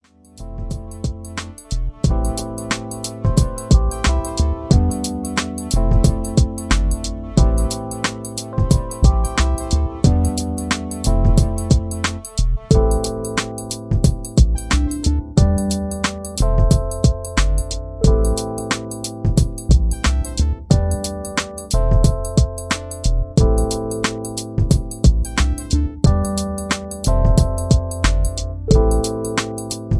Contemporary Jazz Beat